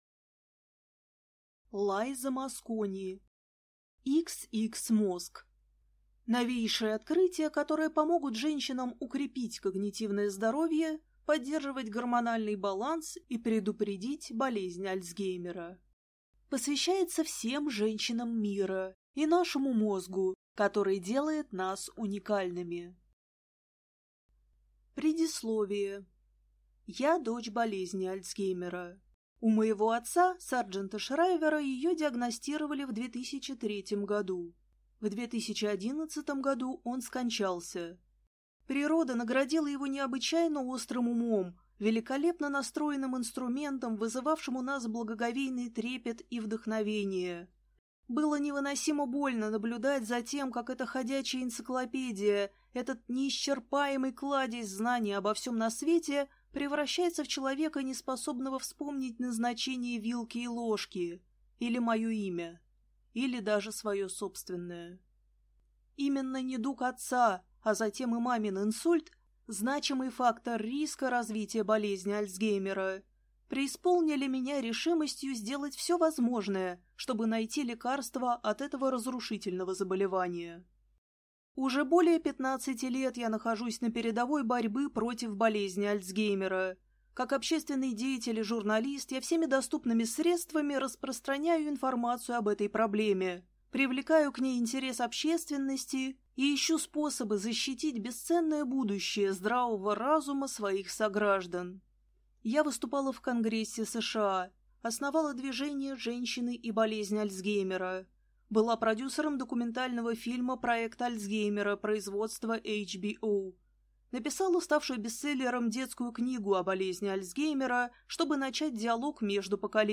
Аудиокнига XX-мозг.